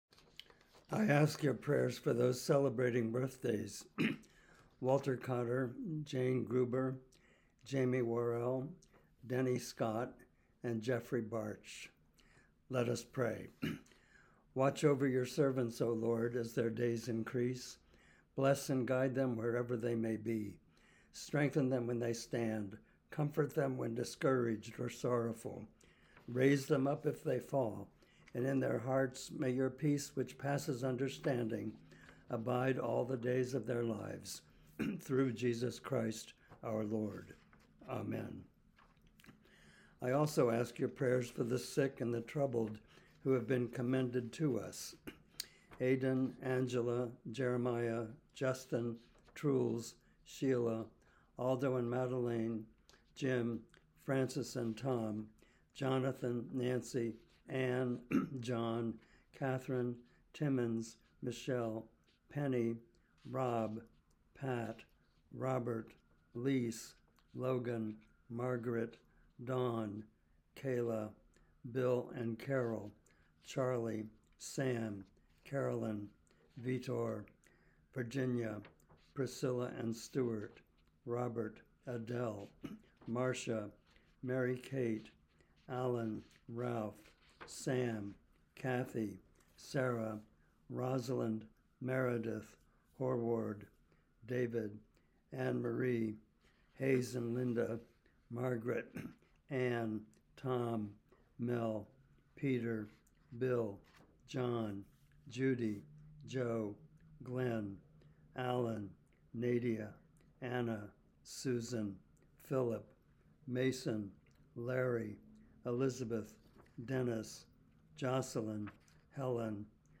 Recording of Weekly Prayers: